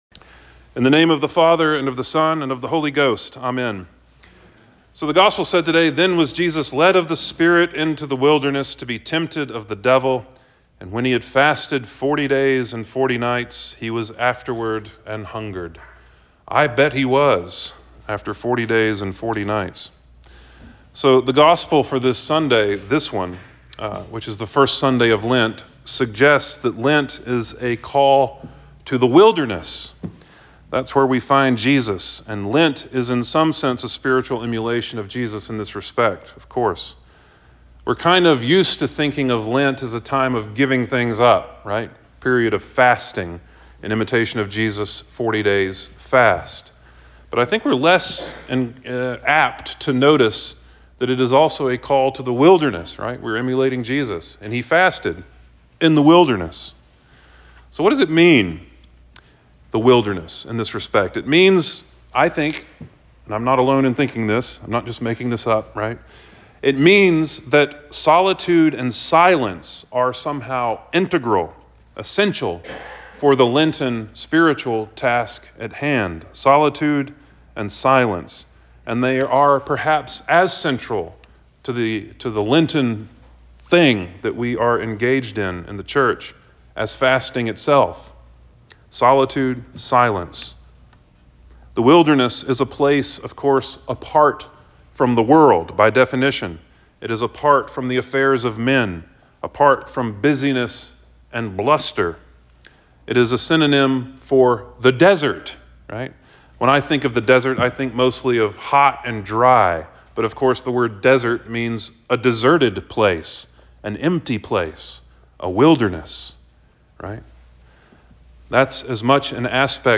All Saints Sermons